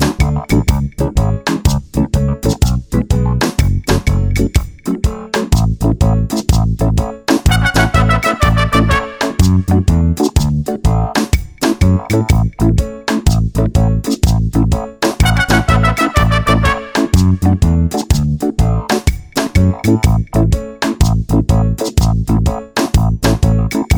minus guitars no Backing Vocals Reggae 4:28 Buy £1.50